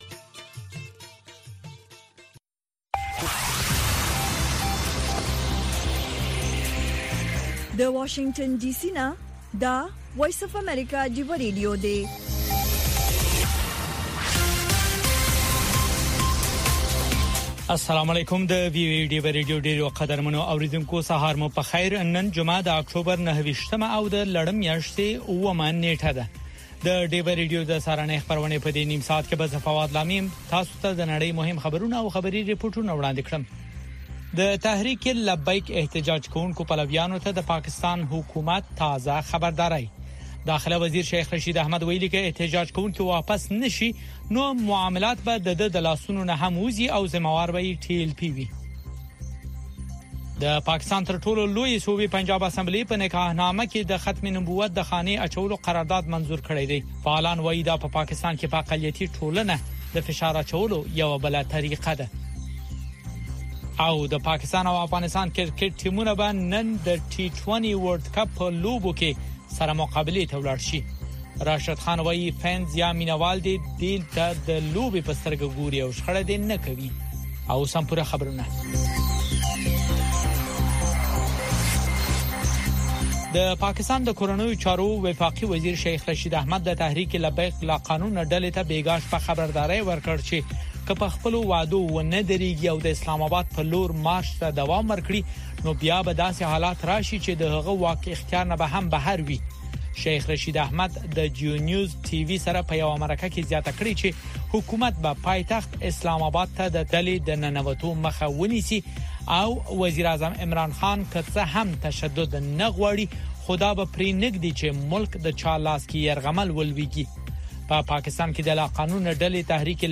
خبرونه
د وی او اې ډيوه راډيو سهرنې خبرونه چالان کړئ اؤ د ورځې د مهمو تازه خبرونو سرليکونه واورئ.